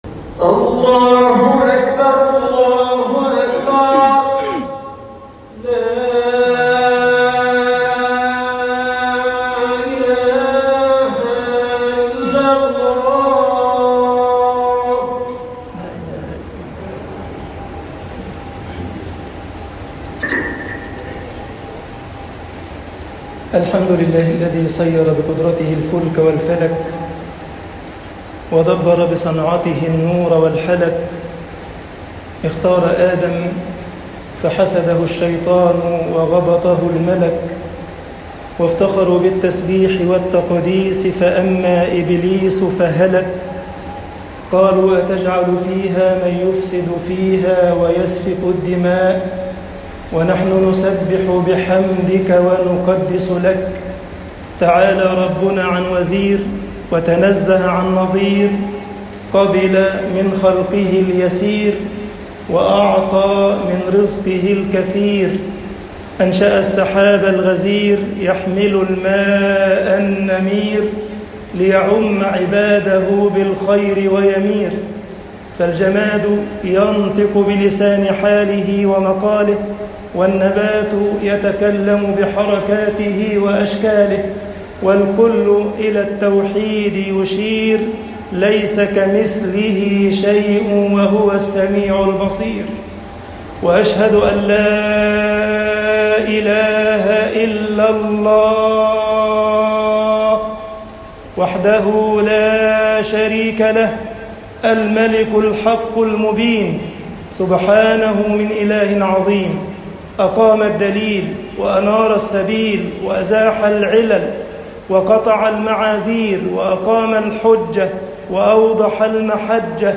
خطب الجمعة - مصر بُشْرَيَاتٌ نَبَوِيَّة